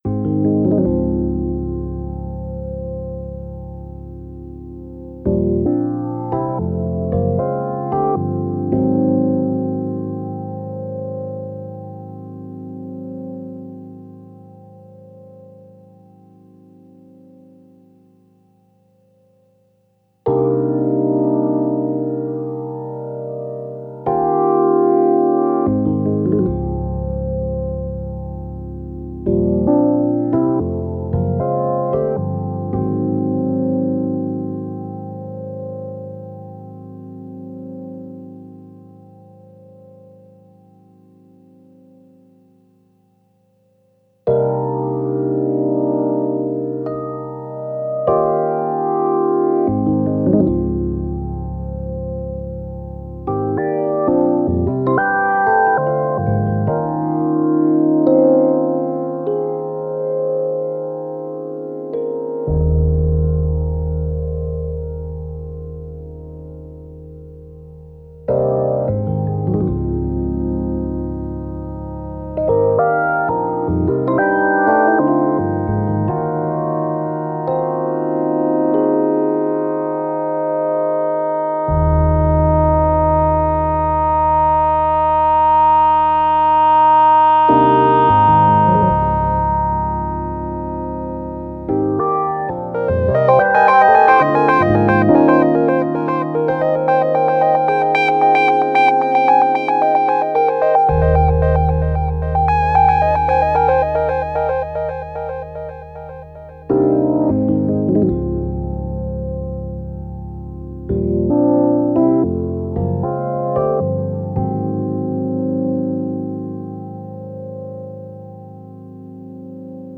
Genre: Lounge, Downtempo.